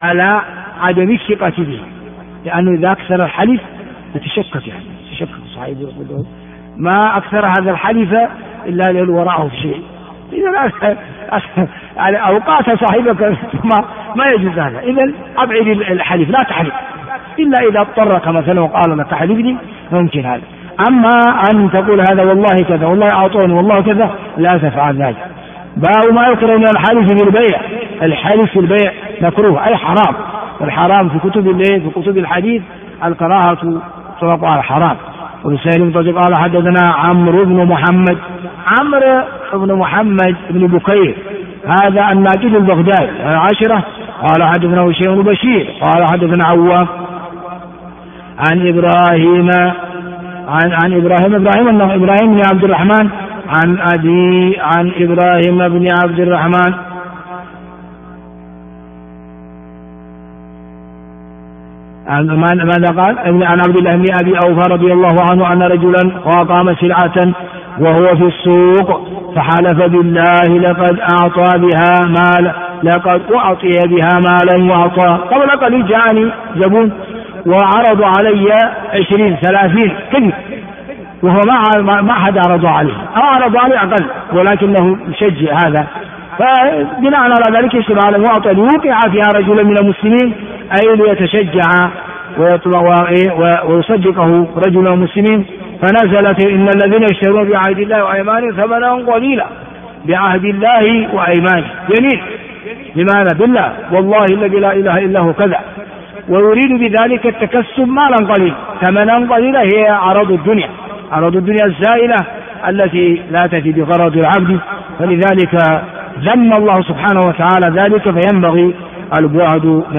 الدرس 144 - كتاب البيوع - بَابُ مَا يُكْرَهُ مِنَ الحَلِفِ فِي البَيْعِ - ح 2088